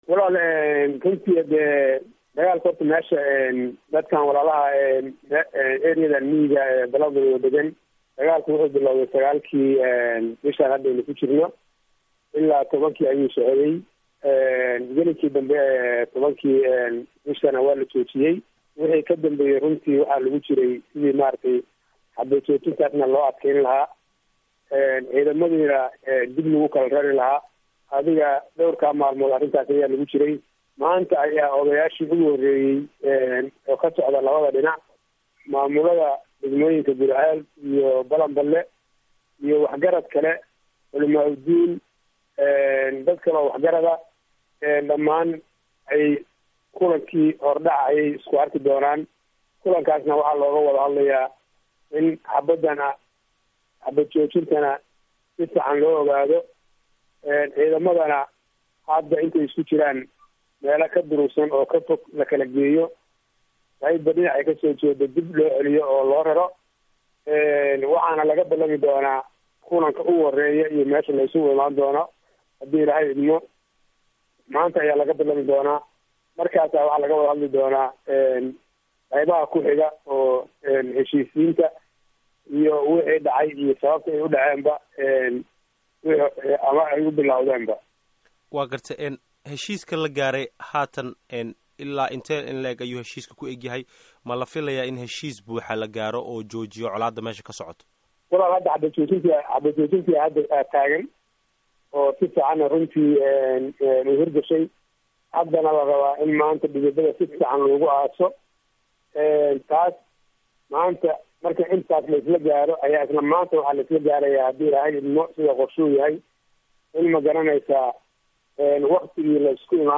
Gudomiyah Gobolka Galgaduud Xusseen Cali Weheliye, ayaa VOAda uga warrmay arrintan.
Waraysiga Guddoomiyaha Galguduud